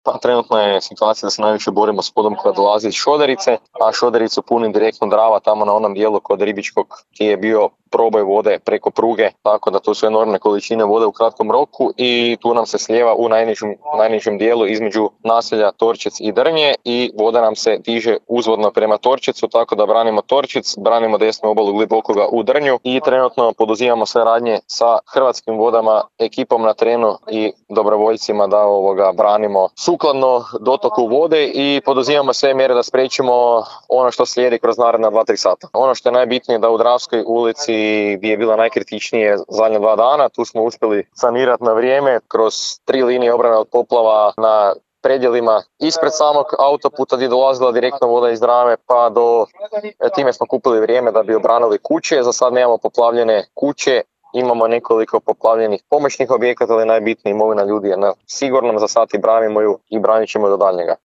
I naselje Torčec je u velikoj opasnosti od poplave. Načelnik Općine Drnje Petar Dombaj kazao je kako trenutno najviše bore s prodorom vode koji dolazi iz Šoderice;